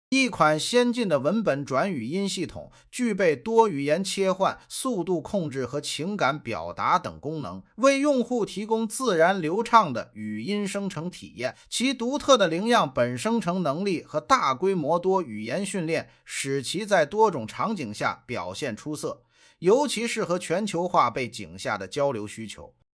4. 单角色语音生成
【生成结果】
a-生成结果.mp3